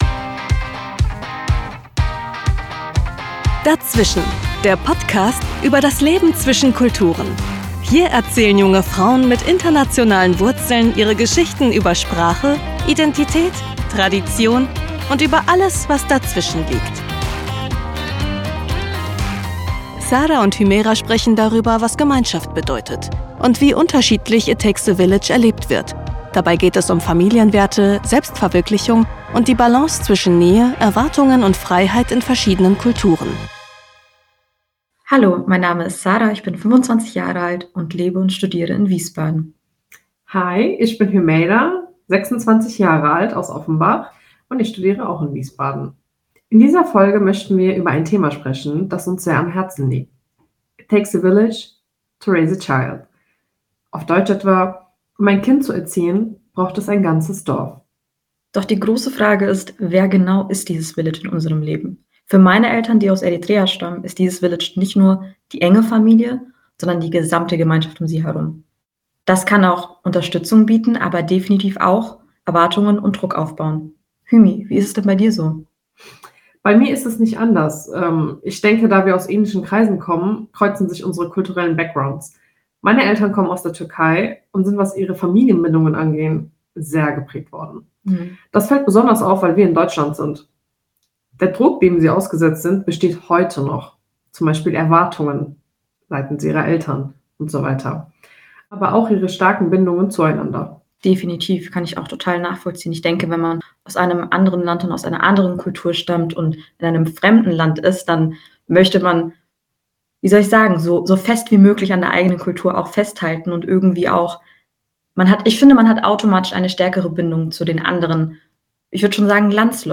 Musik aus der Podcast-Verpackung